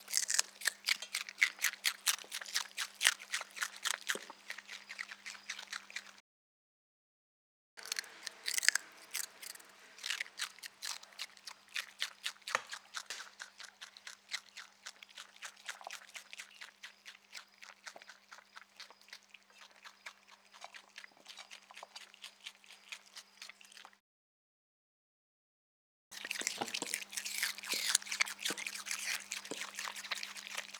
TerraZoo_Mammal_RingTailedLemur_EatingCelery_KMR81i.wav